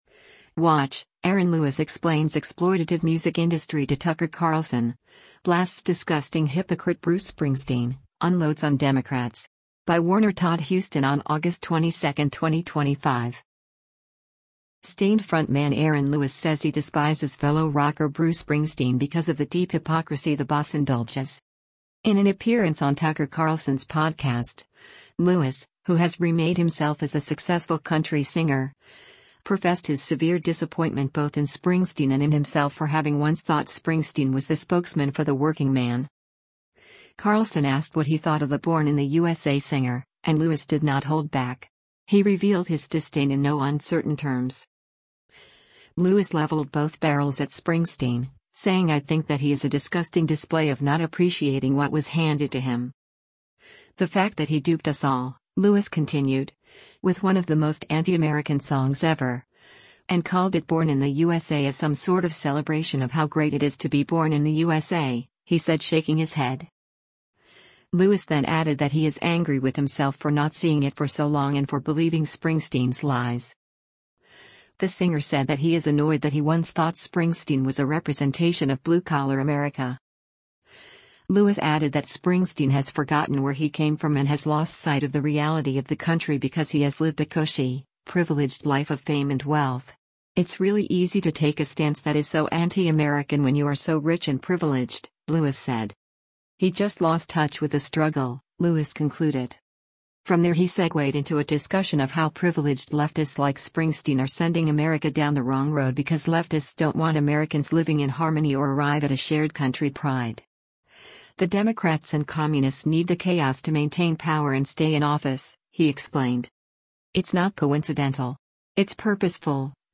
Carlson asked what he thought of the Born in the U.S.A. singer, and Lewis did not hold back.